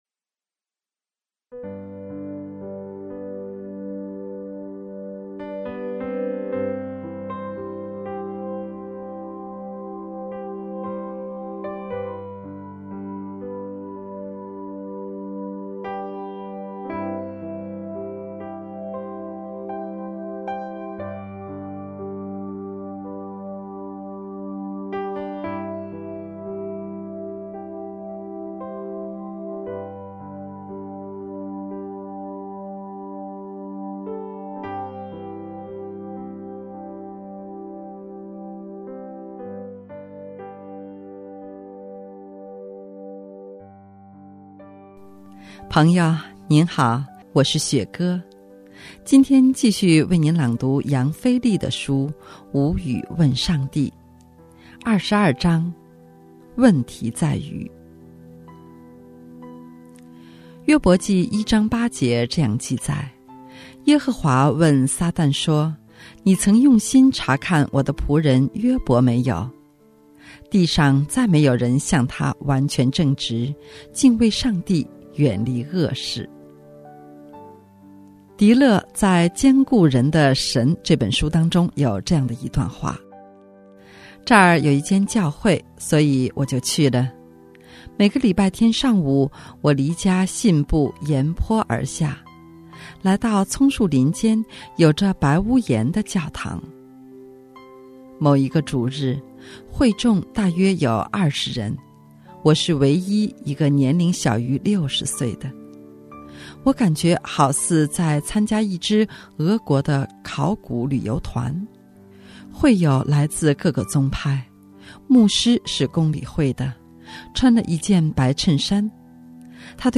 今天继续为你朗读《无语问上帝》。